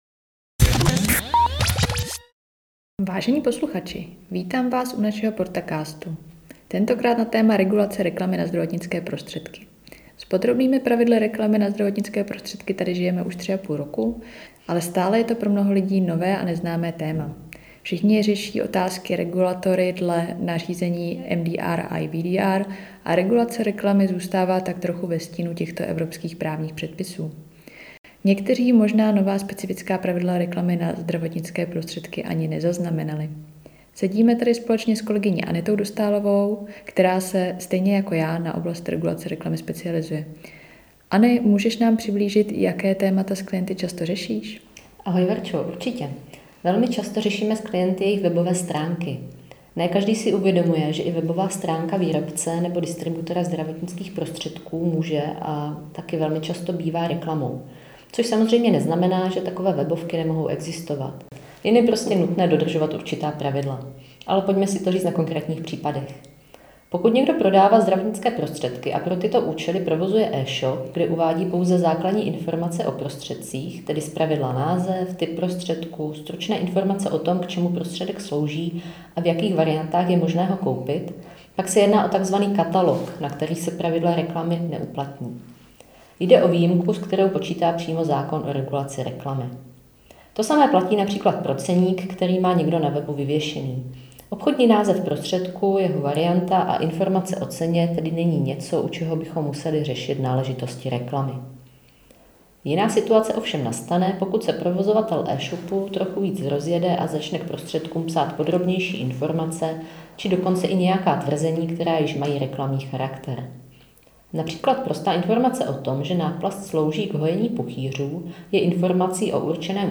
V přibližně osmiminutovém rozhovoru se posluchači dozvědí o praktických zkušenostech z oblasti regulace reklamy na zdravotnické prostředky a o častých problémech, se kterými se při přípravě reklamních materiálů setkáváme.